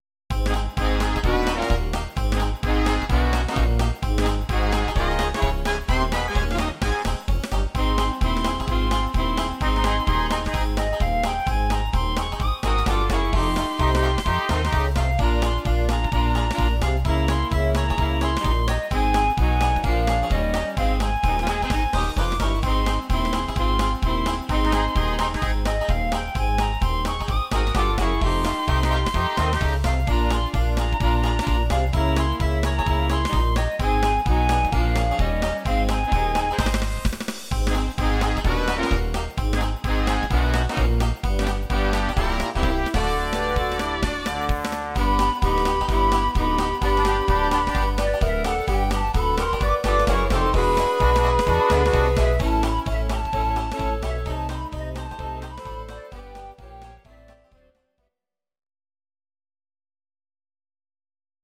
Audio Recordings based on Midi-files
Oldies, Ital/French/Span